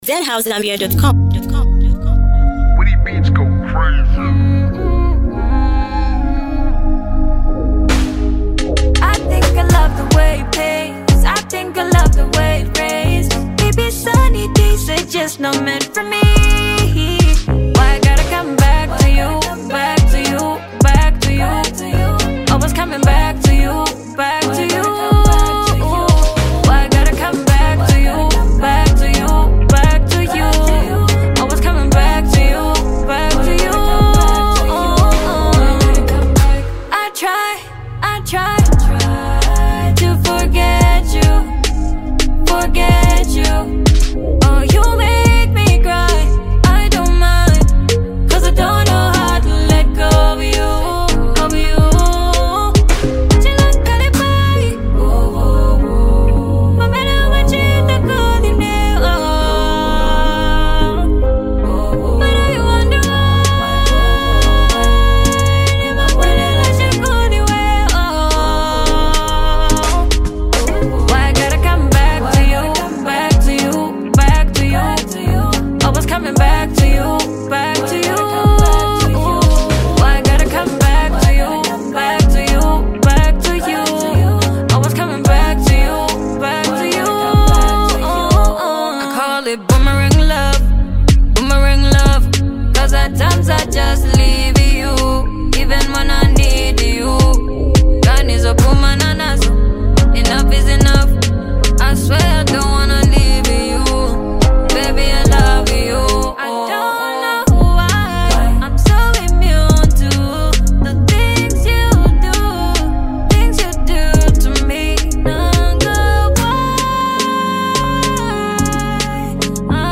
soul and elegance